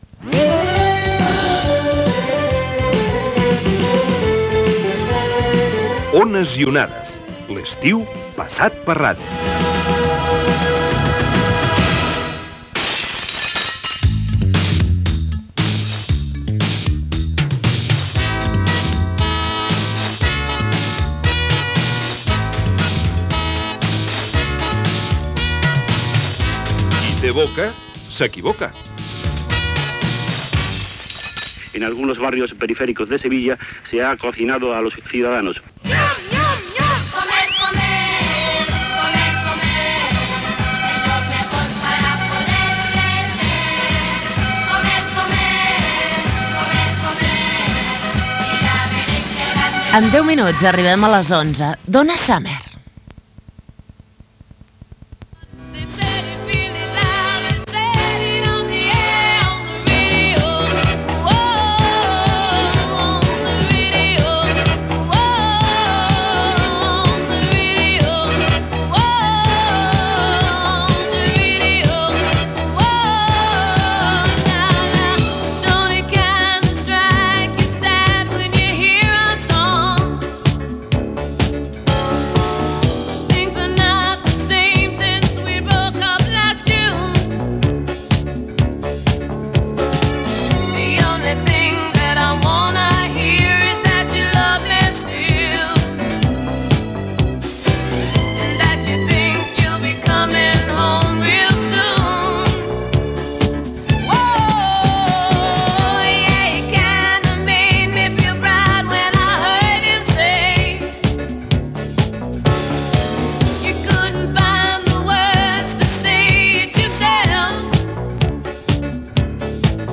Indicatiu del programa, "Qui té boca s'equivoca", tema musical, indicatiu del programa, com passar l'estiu Tinet Rubira i tema musical.
Entreteniment